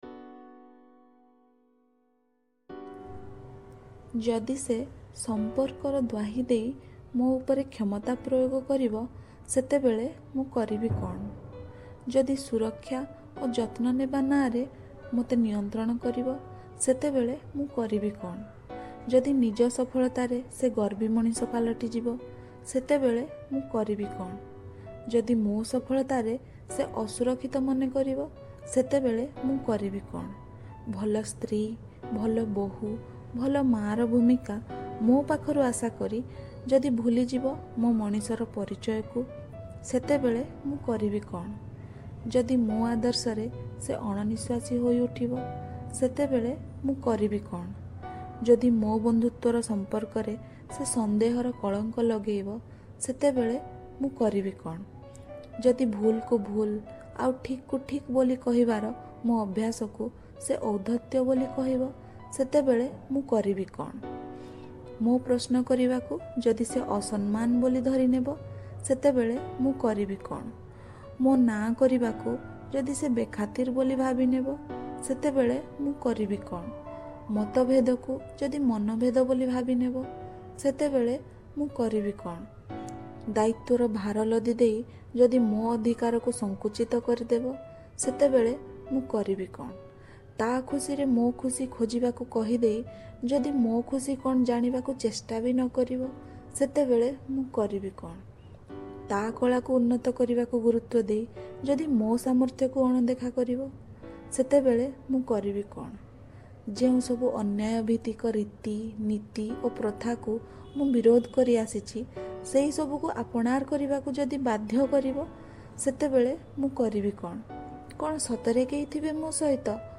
କବିତାଟି ଲେଖିଛନ୍ତି ଓ ପାଠ କରିଛନ୍ତି
କବିତା : ମୁଁ କରିବି କ’ଣ ?